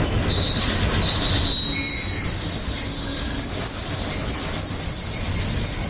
TrainBrakesSFX.wav